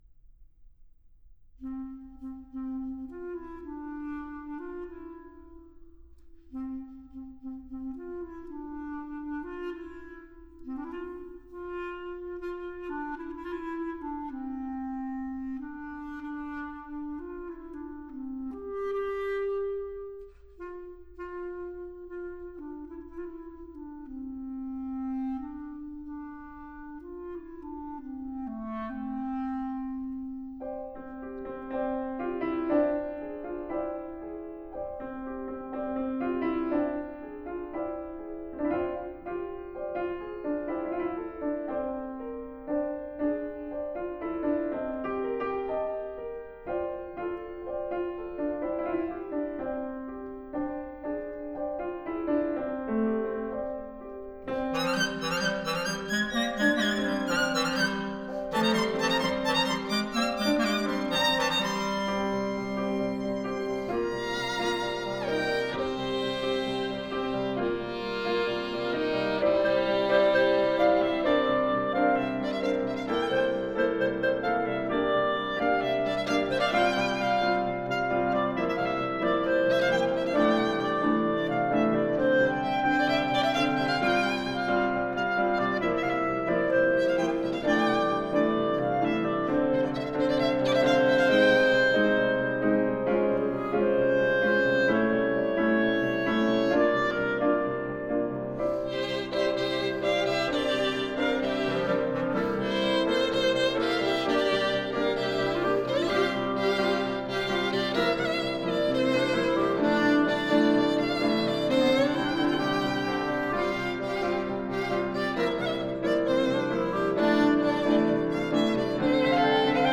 Recorded at the Shalin Liu Performance Center, Rockport, MA